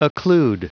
Prononciation du mot occlude en anglais (fichier audio)
Prononciation du mot : occlude